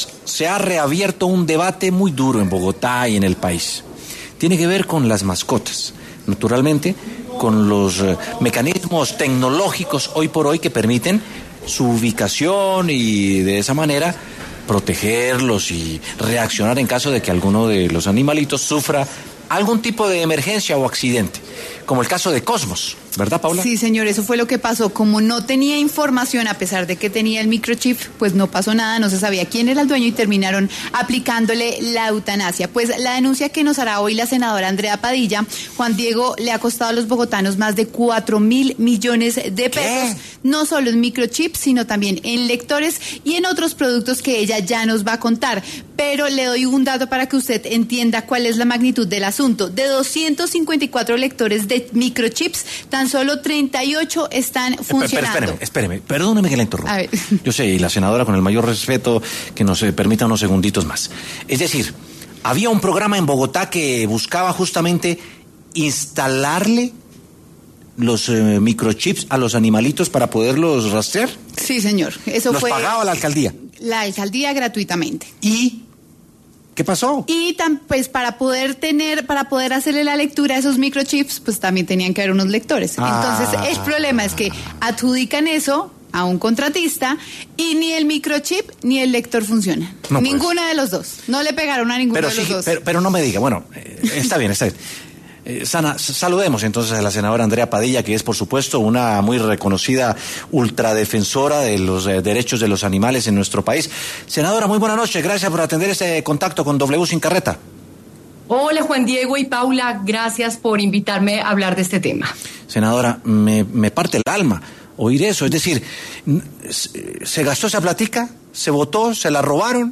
La senadora Andrea Padilla estuvo en los micrófonos de W Sin Carreta para denunciar la inversión de 4.000 millones de pesos, fondos que estaban destinados para implantar un microchip en los animales, de tal forma que se pudieran leer en caso de estar perdidos, un programa que inició en 2006.